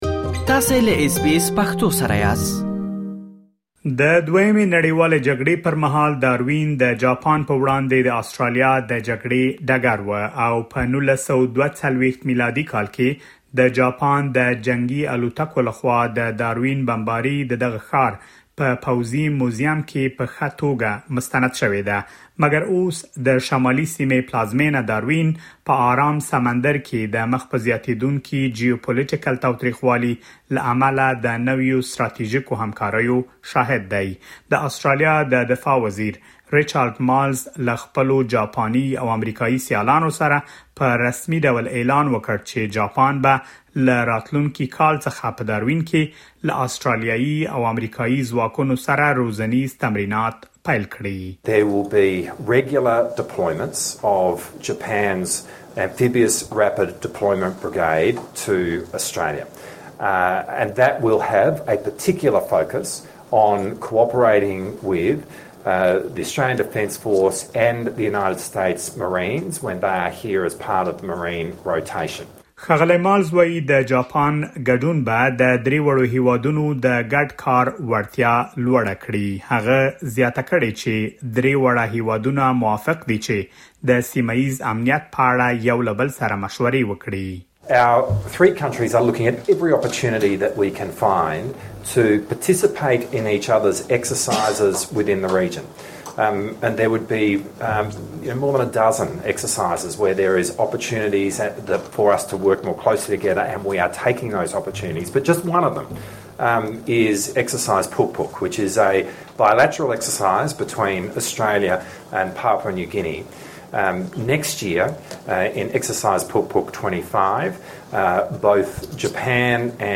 د آسټرالیا د دفاع وزیر، ریچارډ مالز ویلي چې جاپاني ځواکونه به له راتلونکې کال څخه په داروین کې له آسټراليايي او امریکایي ځواکونو سره کډ پوځي تمرینات پیل کړي. لا ډېر معلومات دلته په رپوټ کې اورېدلی شئ.